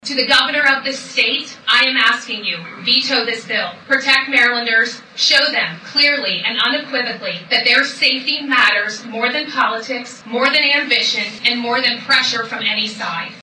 Wicomico County Executive Julie Giordano joined fellow Maryland leaders and impacted families at a press conference at the Harford County Sheriff’s Office Monday to address pending state legislation concerning 287(g) agreements and cooperation with U.S. Immigration and Customs Enforcement (ICE).